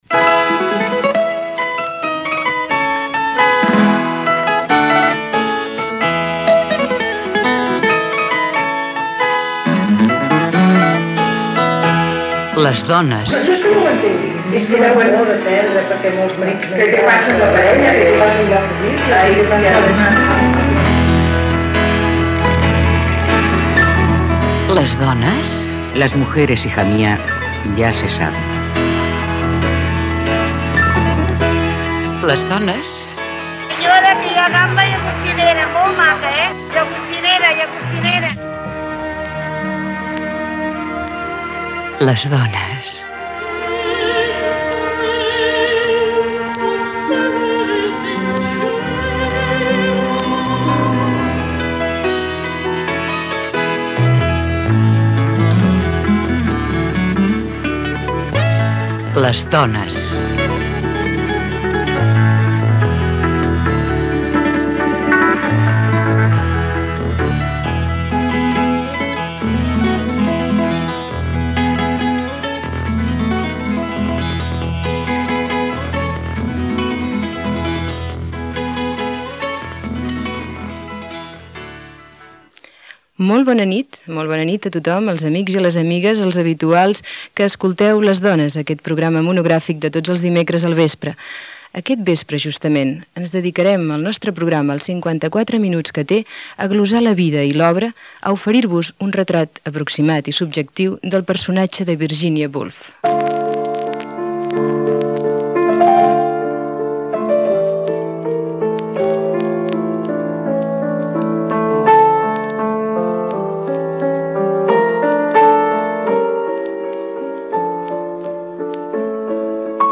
Careta del programa
Divulgació
FM